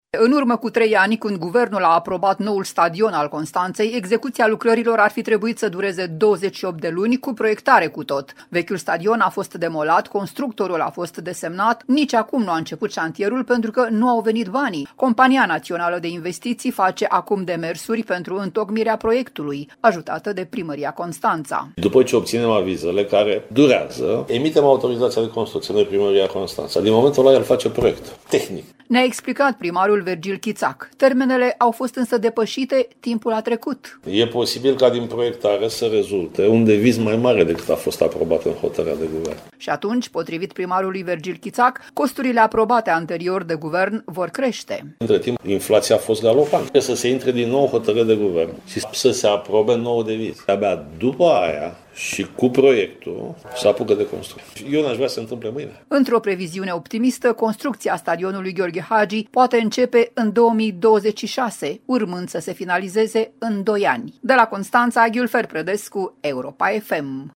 „După ce obținem avizele, care durează, emitem autorizația de construcție, noi, primăria Constanța. Din momentul acela el face proiectul tehnic”, ne-a explicat primarul Vergil Chițac.